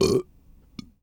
BURP A    -S.WAV